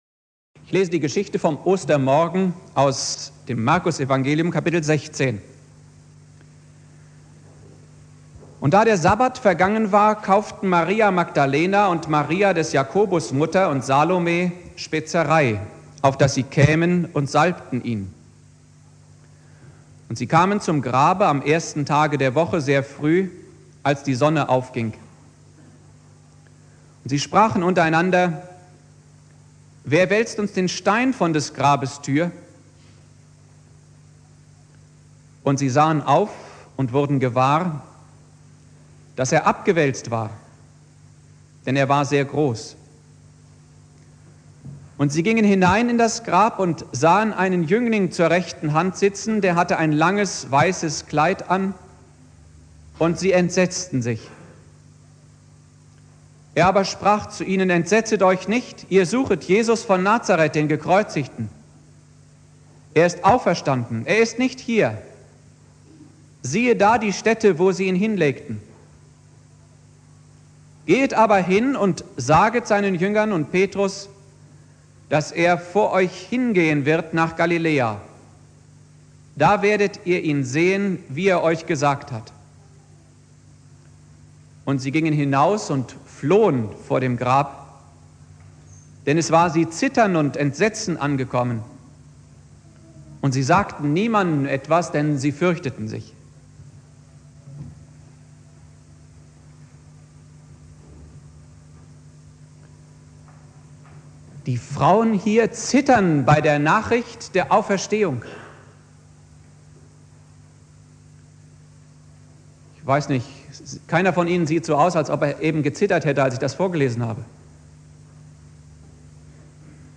Predigt
Ostersonntag Prediger